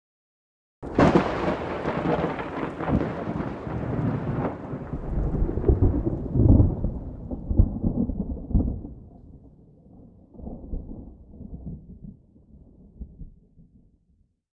thunder-0.ogg